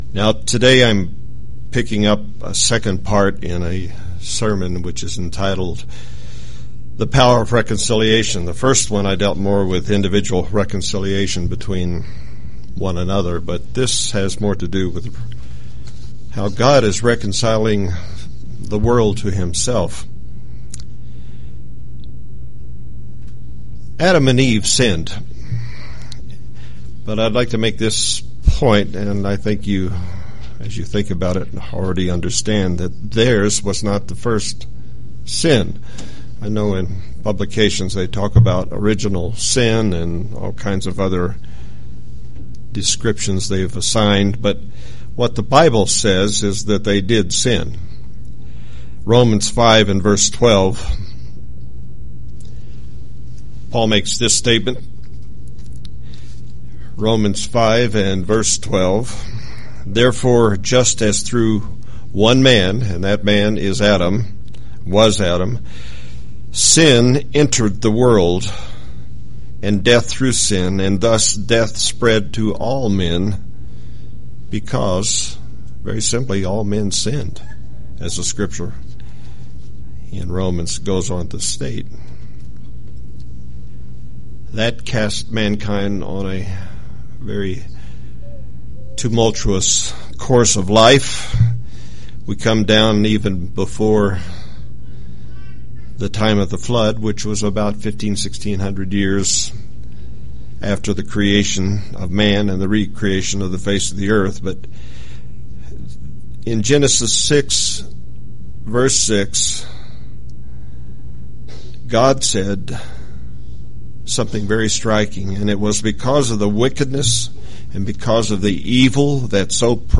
In this second of two sermons, how God is reconciling the world to Himself through Jesus Christ is presented.